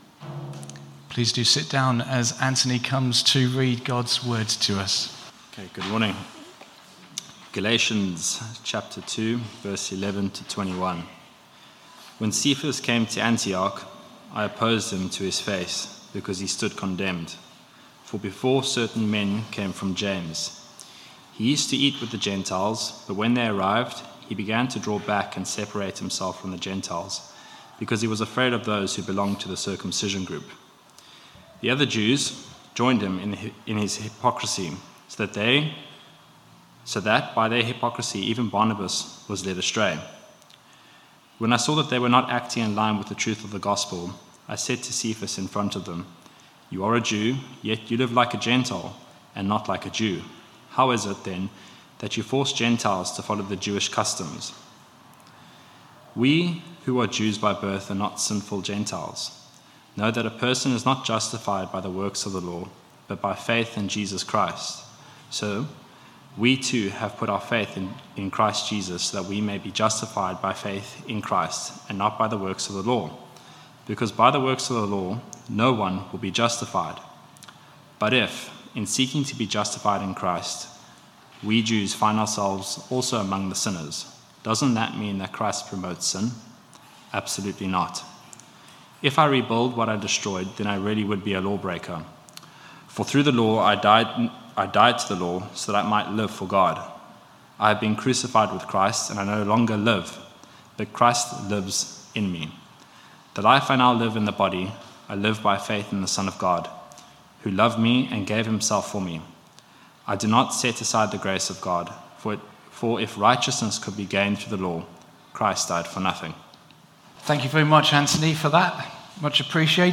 Sermon 9th July 2023 11am gathering
We have recorded our talk in case you missed it or want to listen again.
Family Gathering for Worship 11am 9th July 2023